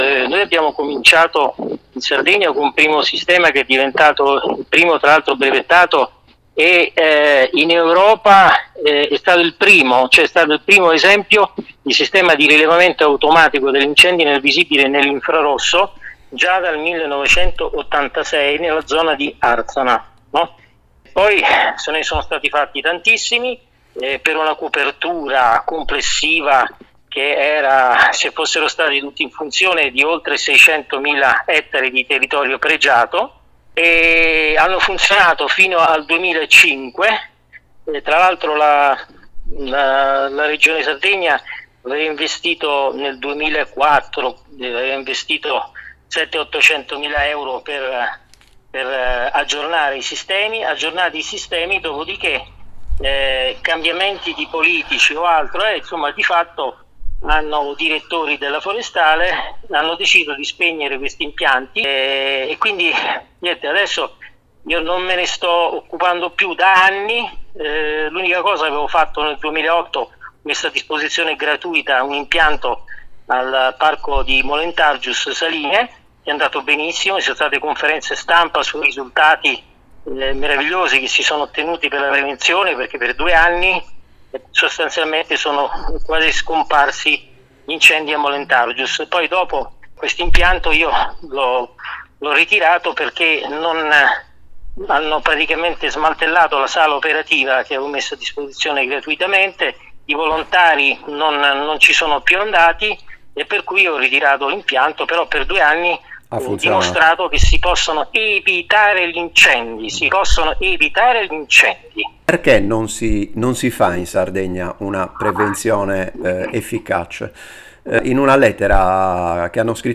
A qualcuno conviene che la Sardegna continui a bruciare? ǀ Audiointervista